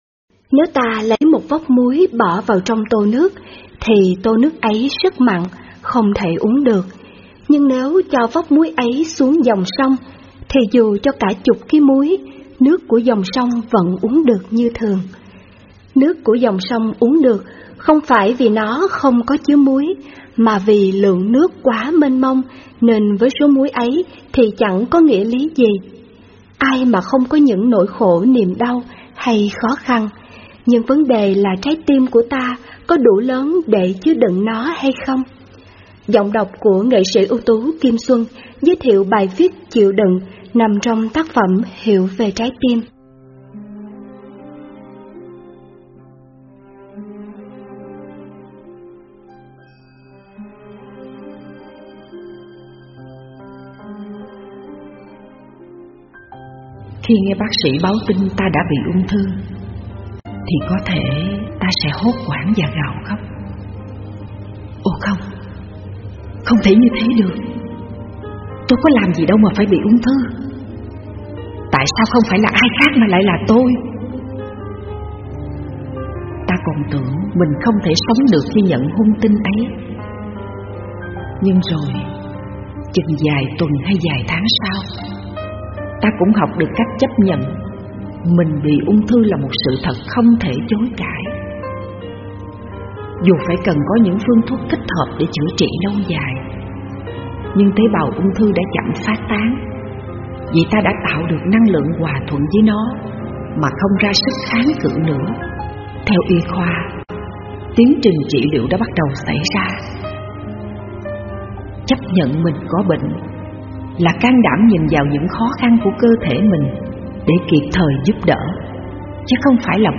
Thích Minh Niệm với Giọng đọc NSUT Kim Xuân, giúp chúng ta tăng khả năng chịu đựng, sự nhẫn nhục và mở rộng dung lượng trái tim Hiểu về trái tim Sách nói mp3